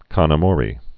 (kŏn ə-môrē, kōn ä-mōrā)